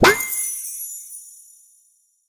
magic_pop_open_05.wav